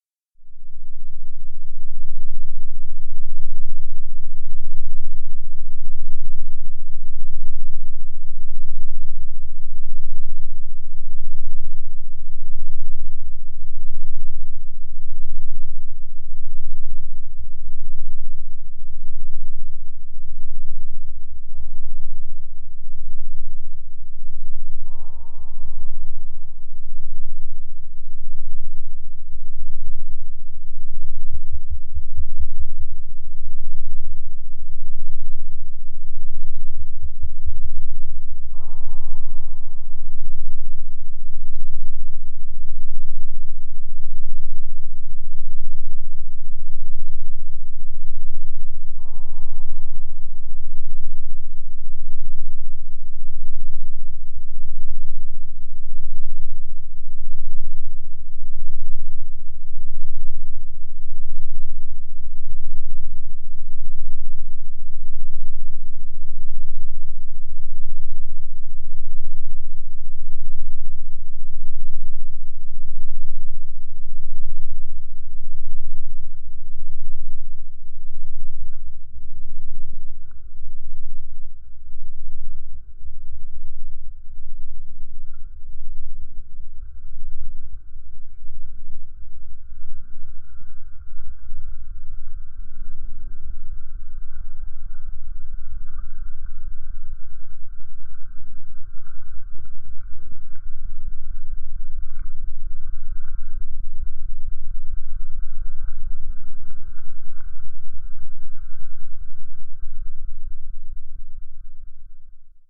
Soundscape Series